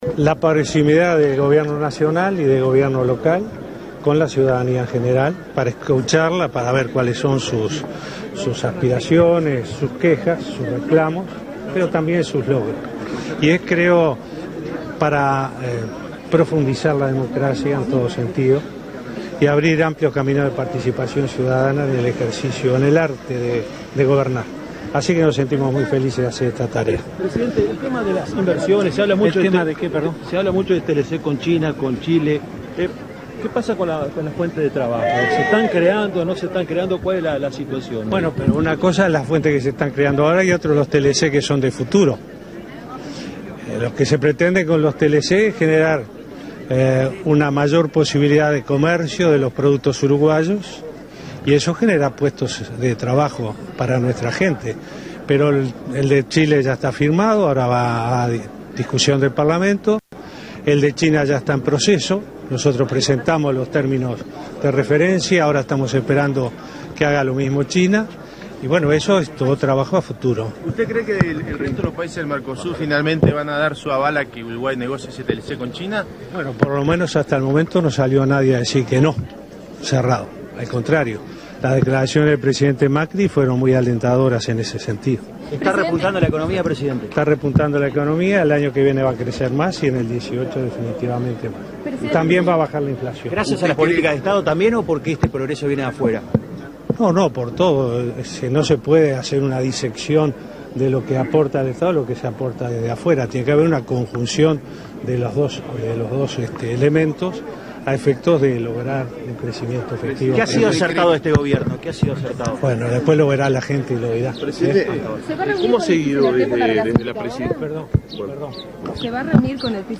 Además, en rueda de prensa, el presidente habló de los TLC, de Venezuela, de Alas Uruguay y del diputado frenteamplista Gonzalo Mujica, entre otros temas:
VAZQUEZ-completo-en-San-Jose.mp3